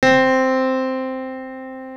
Keys (4).wav